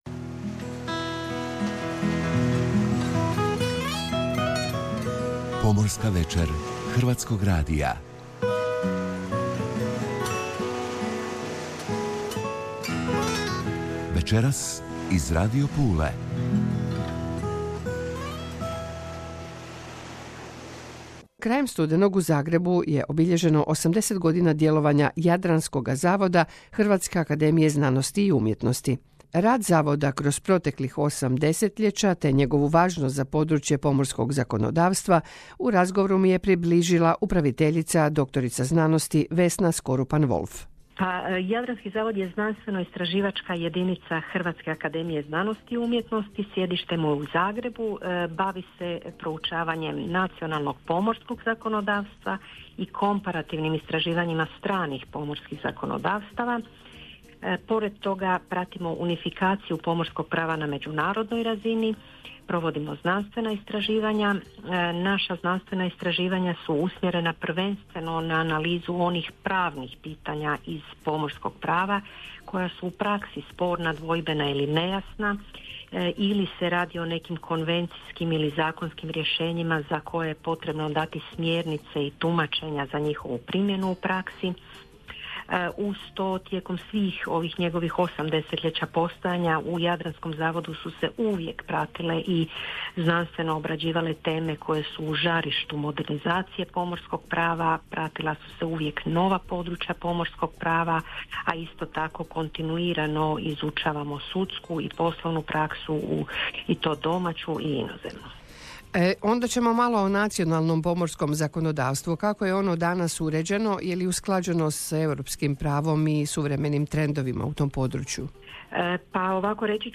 prvog programa Hrvatskog radija iz studija Pula